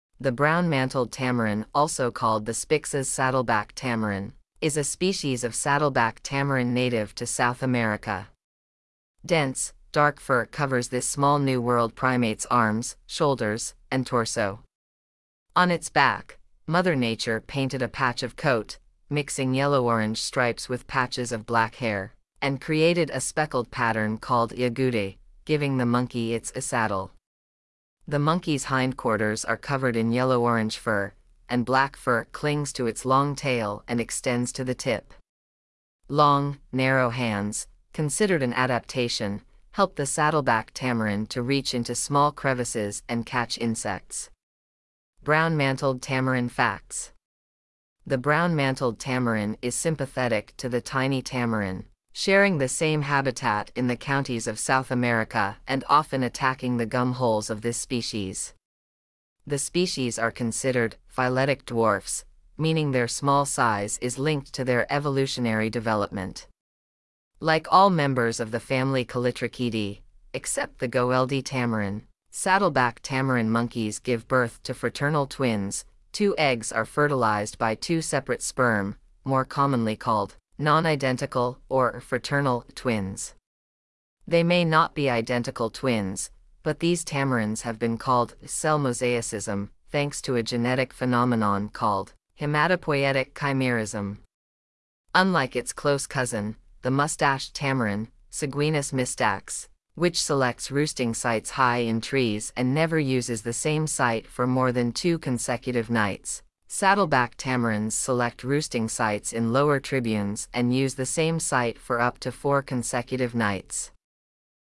Brown-mantled Tamarin
brown-mantled-tamarin.mp3